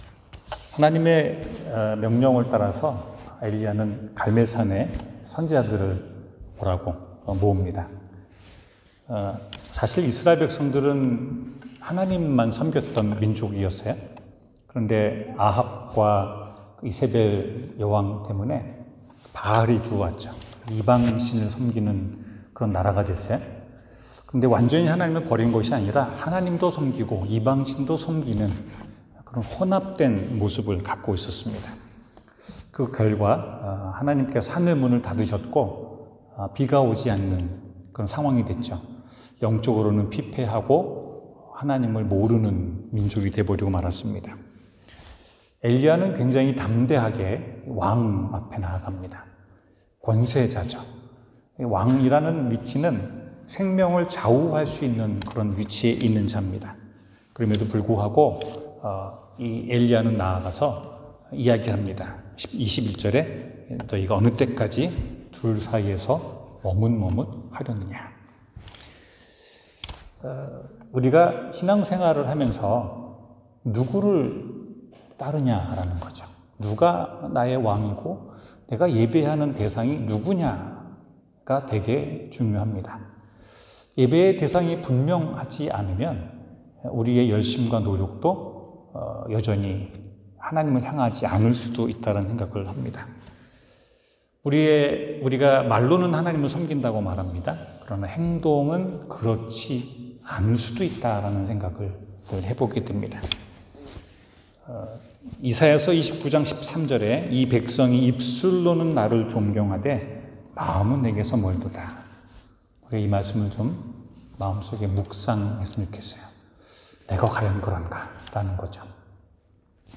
열왕기상 18:19-29 설교